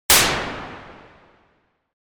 特にアンプエンベロープとフィルターエンベロープのディケイを短くサステインを０にすることで単発の効果音のような音を作り出せることができます。
銃声のような音
1. ノイズ
9. 音程　＝　C3のド